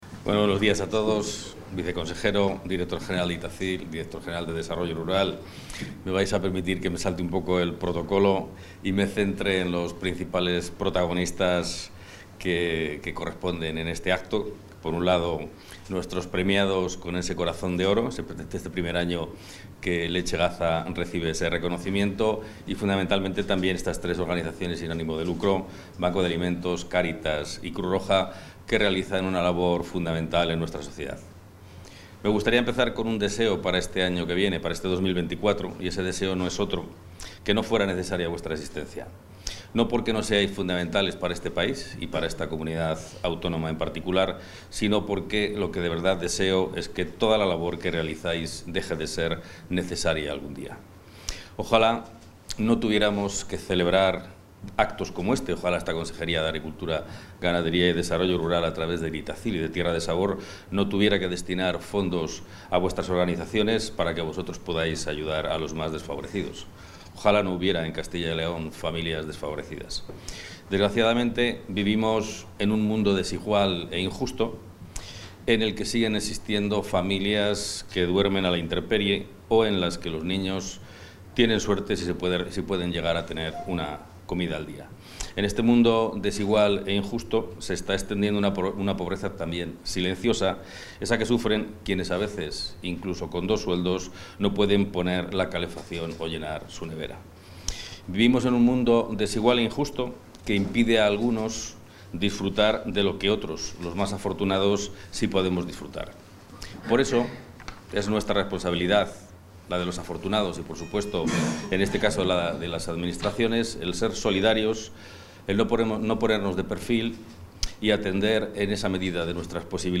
Intervención del consejero.